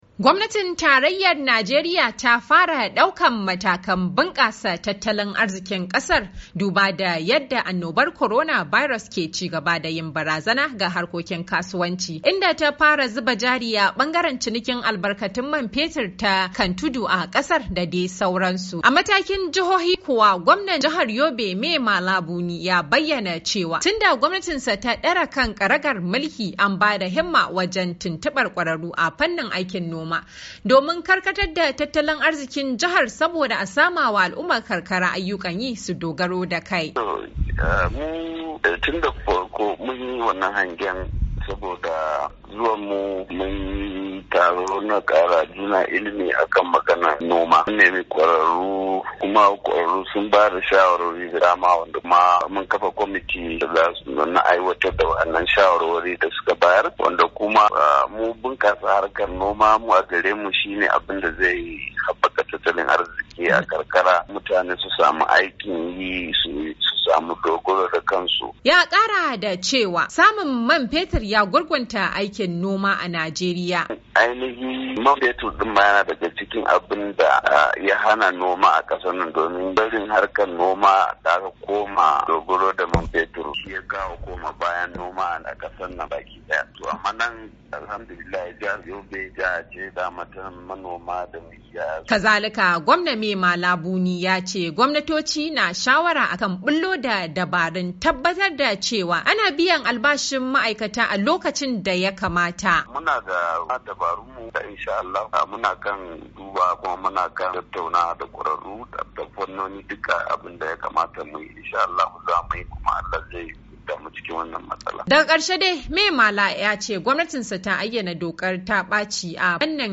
cikakken rahoton